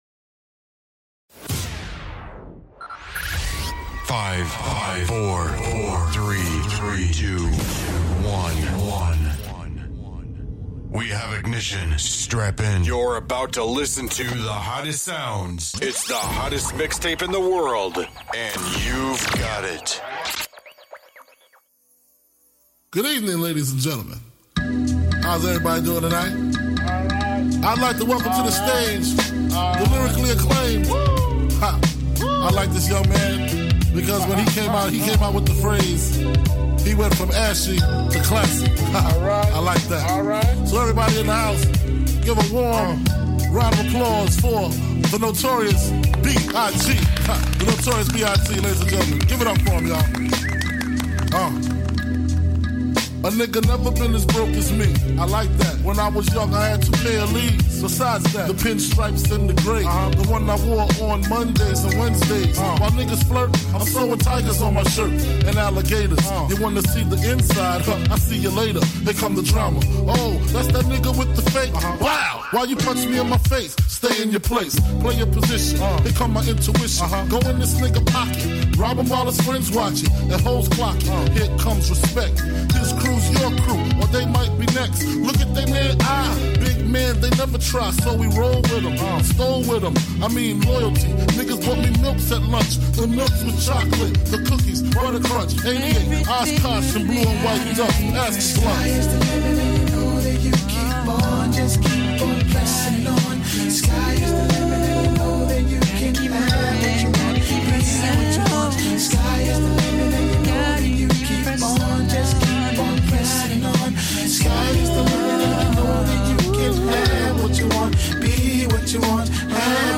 Constantly dropping gemz in your ear holes Be a guest on this podcast Language: en Genres: Music , Music Commentary , Music Interviews Contact email: Get it Feed URL: Get it iTunes ID: Get it Get all podcast data Listen Now...